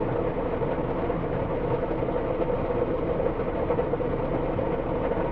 drill.ogg